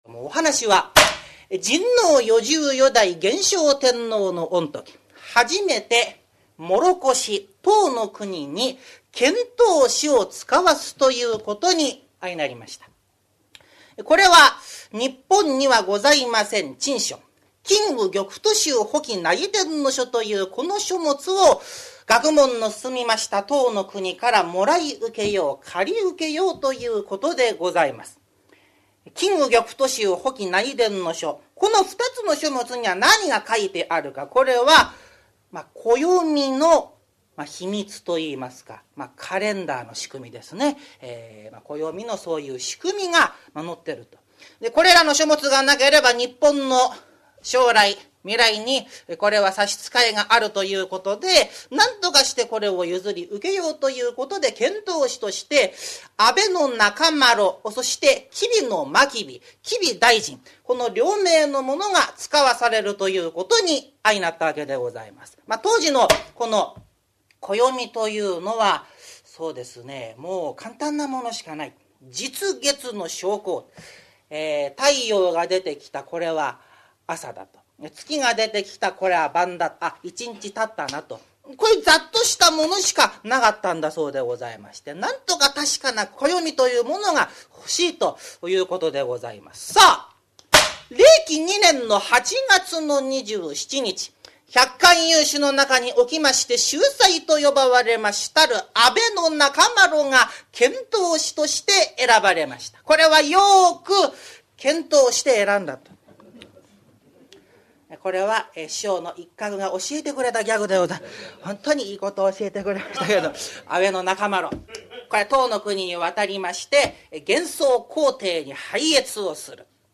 ハリセンで釈台を叩き「パパン」という音を響かせて調子良く語る、江戸時代から伝わる日本伝統の話芸「講談」。講談協会に所属する真打を中心とした生粋の講談師たちによる、由緒正しき寄席で行われた高座を録音した実況音源！